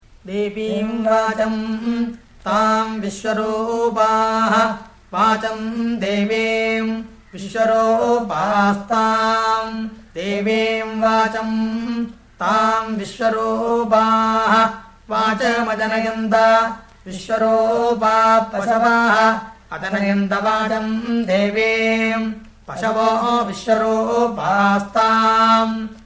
Vikrti Recitation
00-veda2-vkrti-ratha.mp3